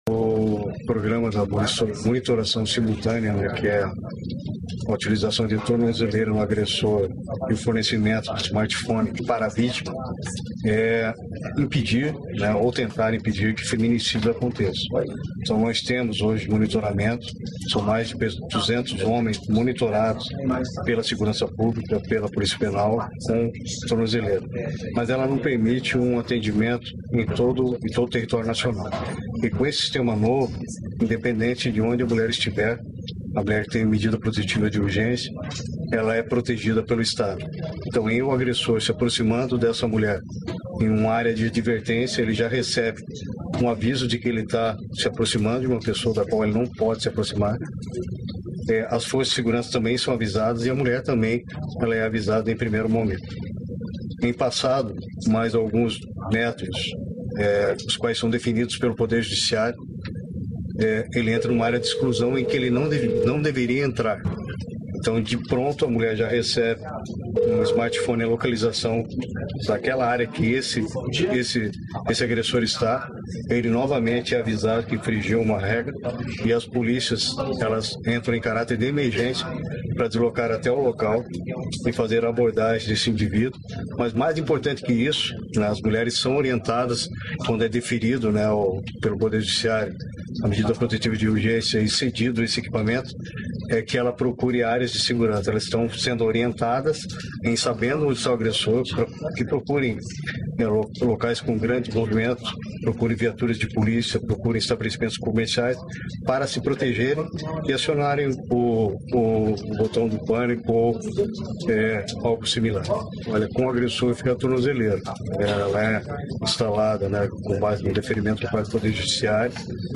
Sonora do secretário estadual da Segurança Pública, Hudson Teixeira, sobre o uso de tecnologia contra o feminicídio